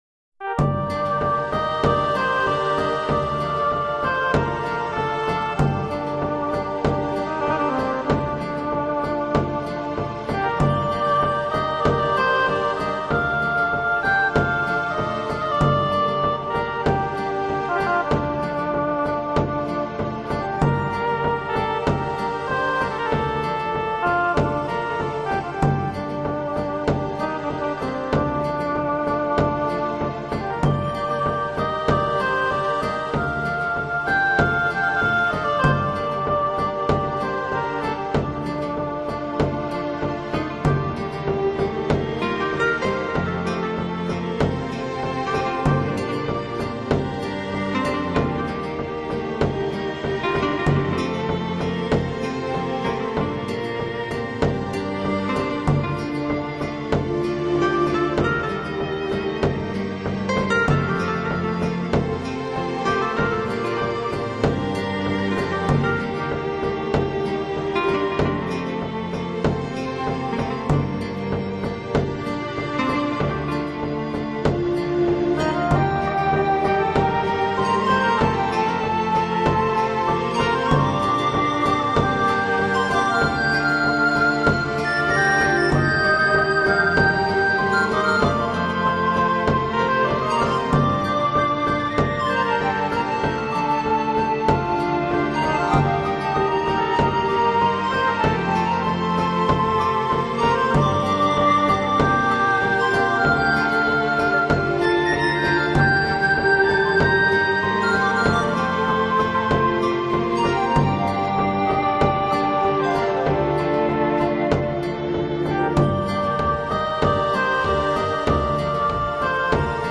类型：NewAge
初听此专辑，没有很深的印象，平静，旋律平坦。
音乐的主题是慢慢的展开的，不紧不慢的，柔柔的，缓缓的，让我们感受到作者的情感，旋律的优美。